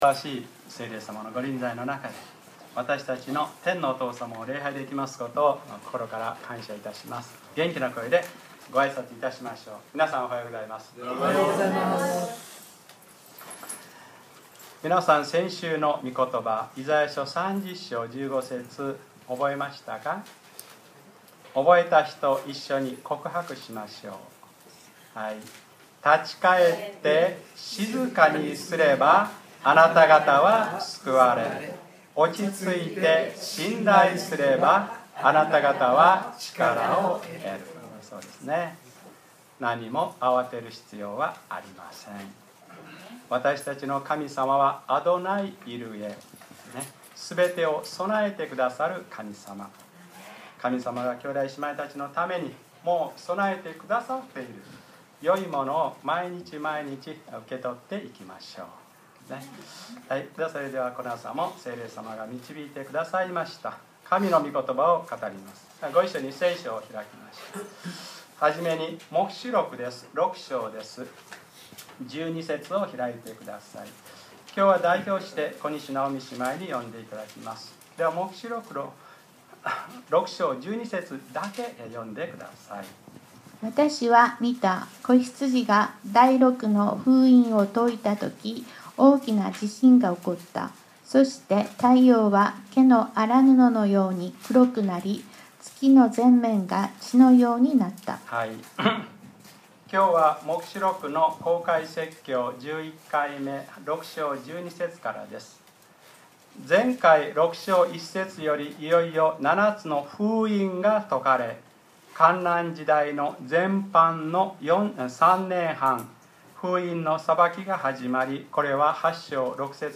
2012年8月26日(日）礼拝説教 『黙示録11/ 悔い改めて神を慕い求めなさい』